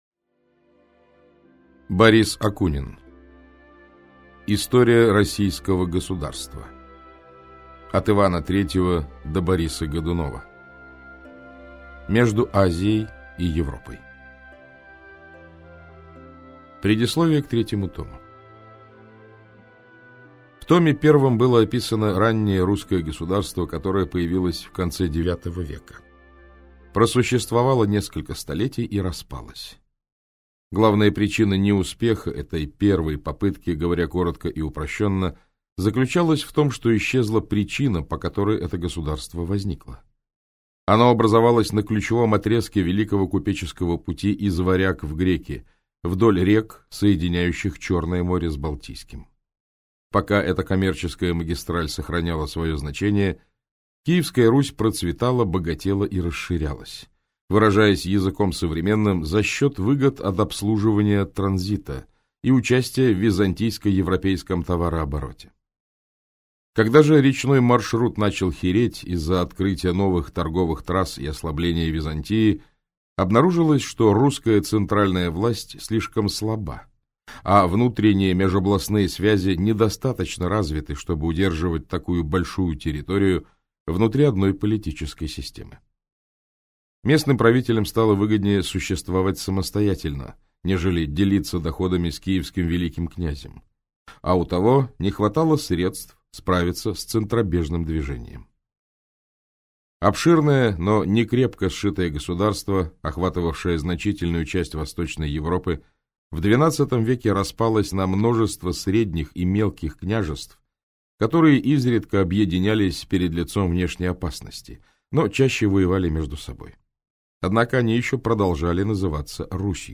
Аудиокнига Между Азией и Европой. История Российского государства. От Ивана III до Бориса Годунова - купить, скачать и слушать онлайн | КнигоПоиск